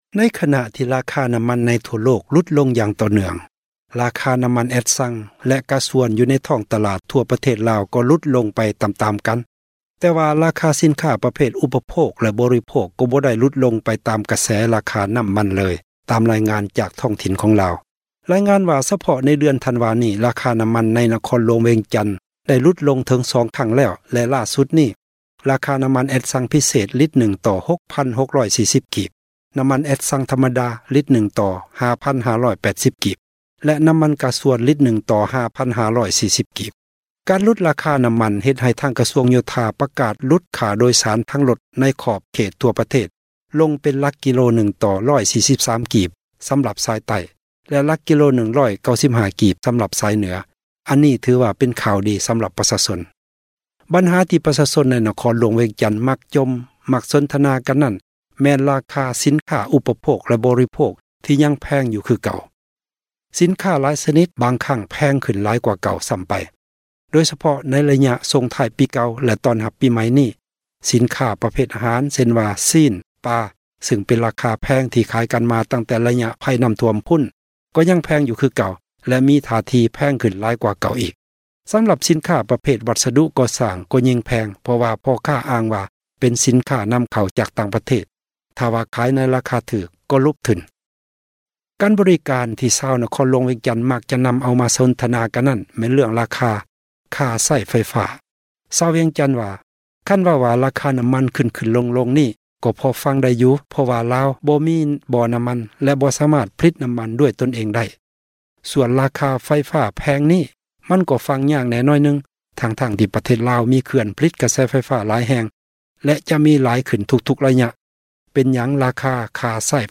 ຣາຄານໍ້າມັນ ຫລຸດລົງ ແຕ່ ສິນຄ້າ ຍັງແພງຢູ່ — ຂ່າວລາວ ວິທຍຸເອເຊັຽເສຣີ ພາສາລາວ